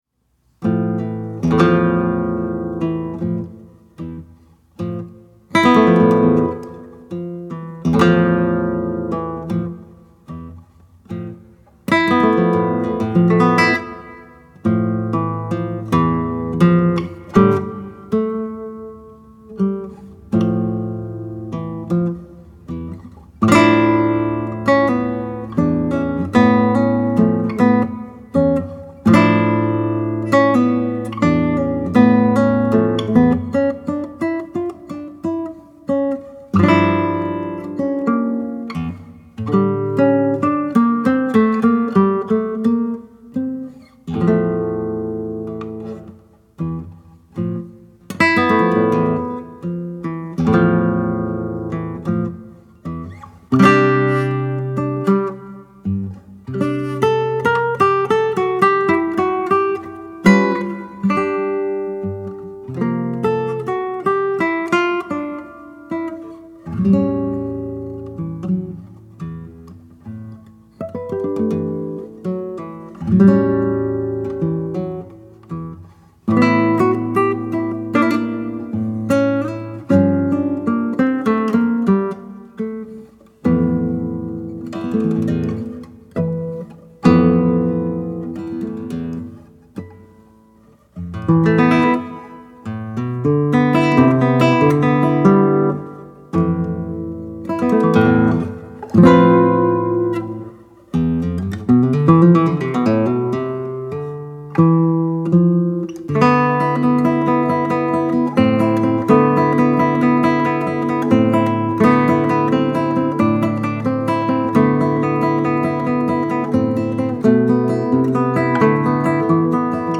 Despite it being a short and somber piece, it is packed full of exquisite textures, colors, and harmonies.
Homenaje was one of the early “serious” pieces I learned when I started learning classical guitar in earnest.